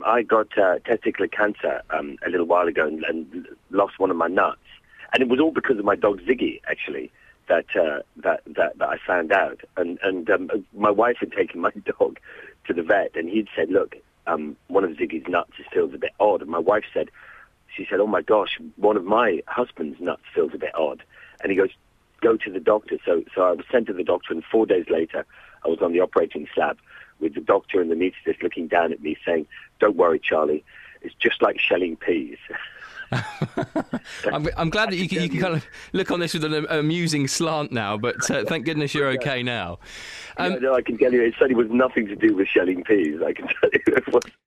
The interview begins around 18 minutes in...